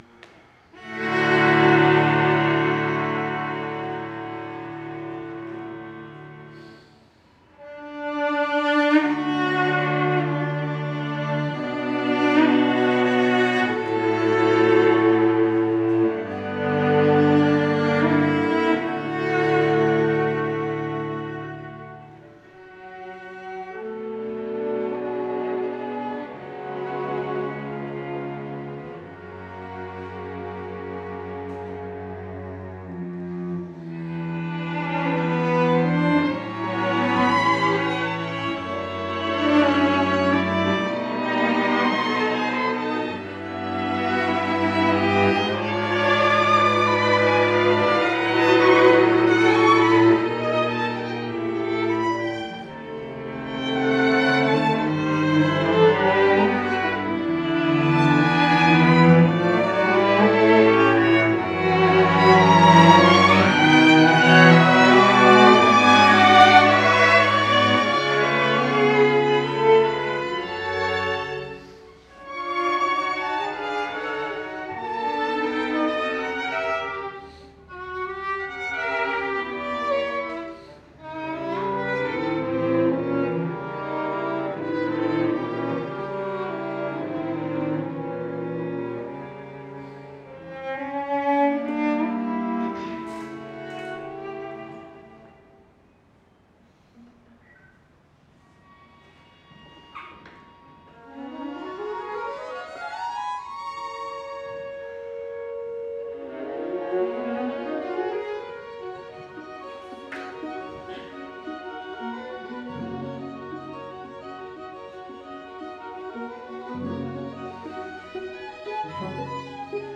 Violin
Viola
Cello 6:58 I. Sehr langsam 6:05 II.